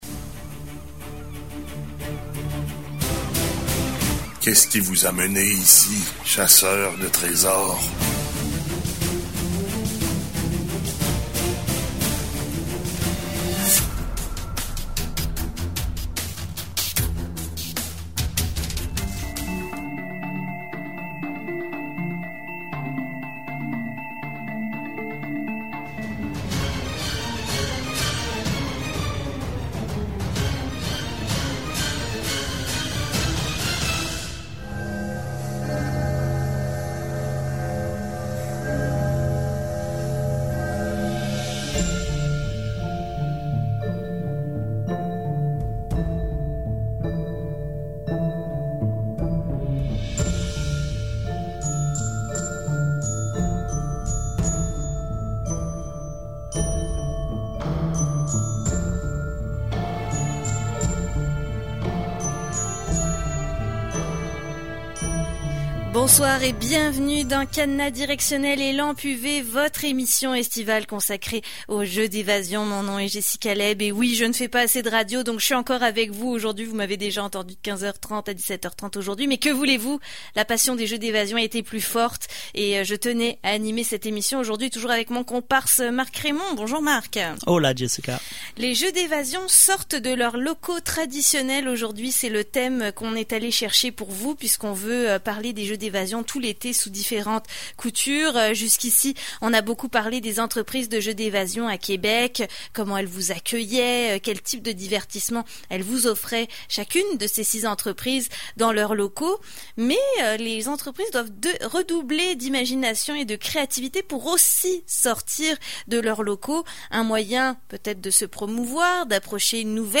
Tu veux réécouter nos voix suaves qui te parlent de ton passe-temps favoris ?